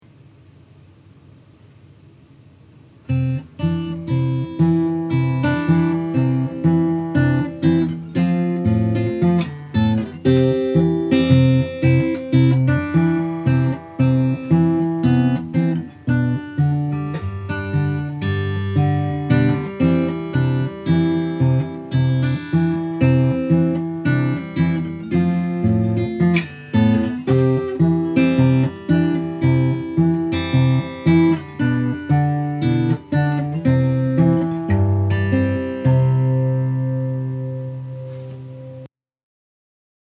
This guitar is my Concert model guitar with a cutaway.
The guitar has been played at my home studio and has excellent volume and projection. It is a finger-style guitar with even tone and volume throughout the musical range.
OKH-Maple-concert.mp3